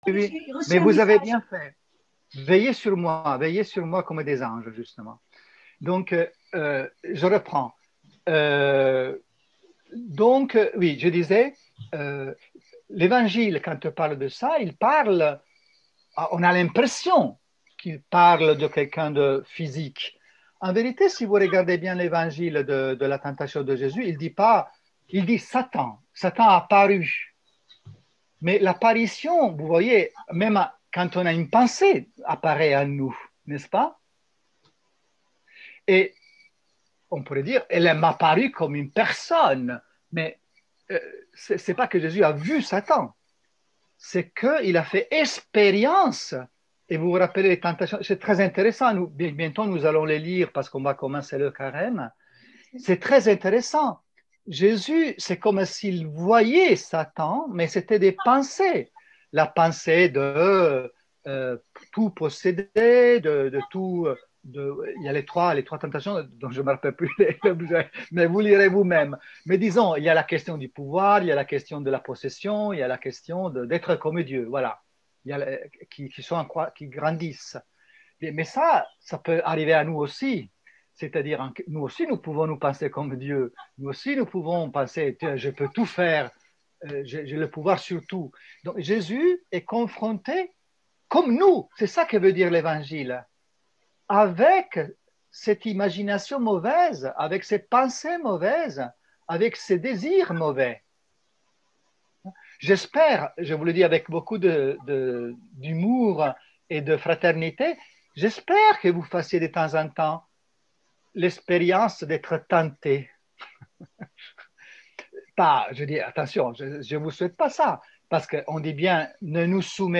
Cours n°5 audio IG modifié du 09 02 21